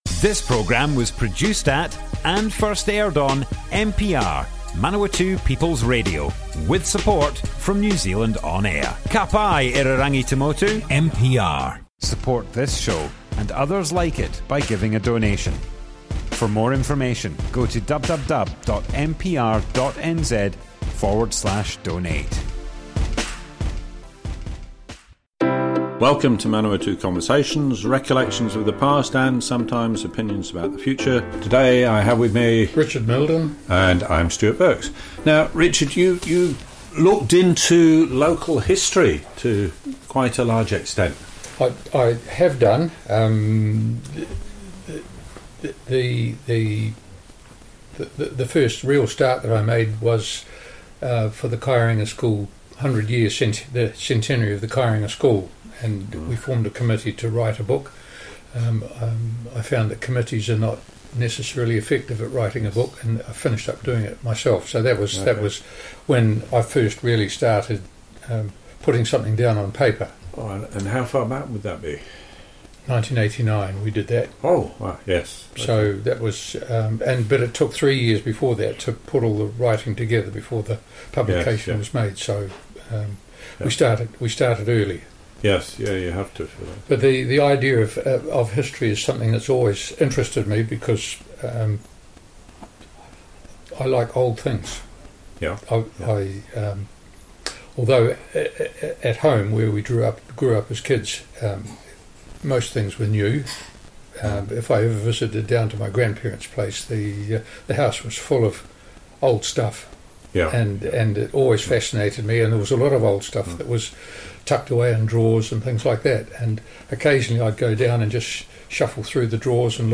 Manawatu Conversations More Info → Description Broadcast on 7th September 2021.
oral history